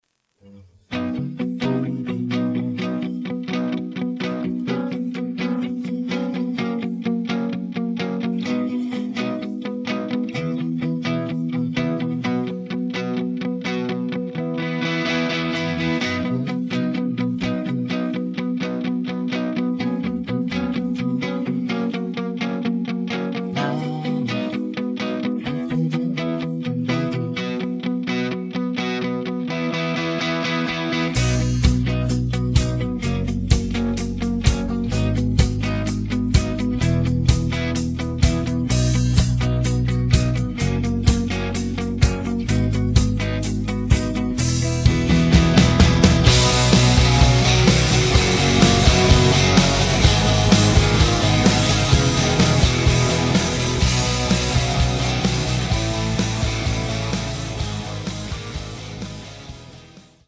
음정 원키 3:37
장르 가요 구분 Voice Cut